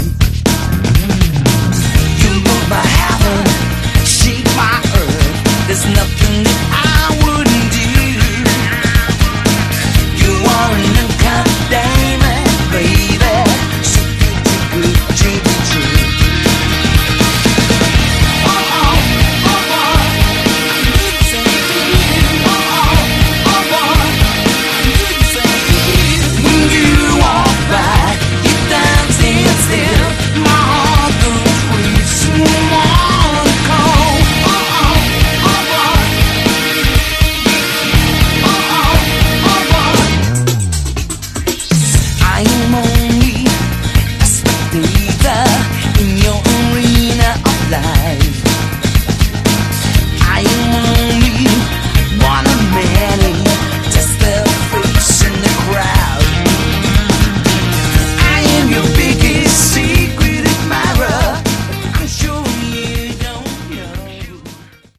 Category: AOR
lead vocals, guitar
drums